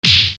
FIST.wav